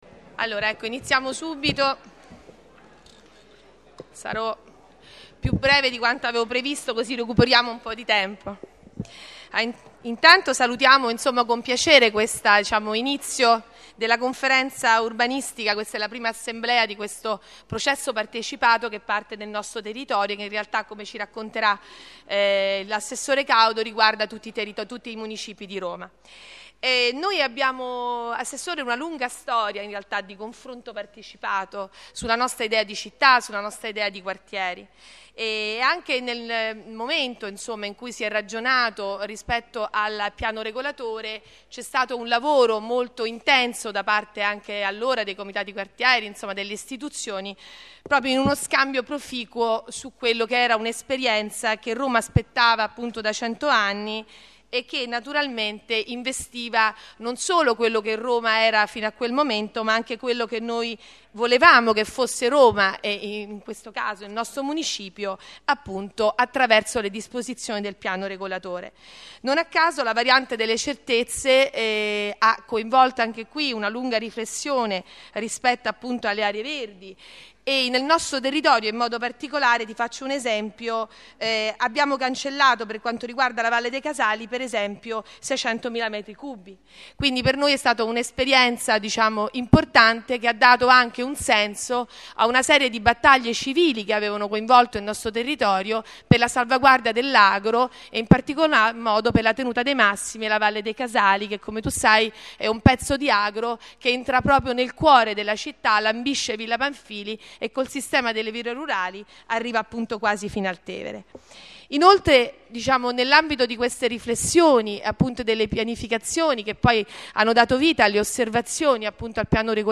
sala-520Registrazione integrale dell'incontro svoltosi il 16 luglio 2014 presso l'Istituto Italiano di Studi Germanici in Viale delle Mura Gianicolensi 11
01-maltese   Cristina Maltese, Presidente Municipio XII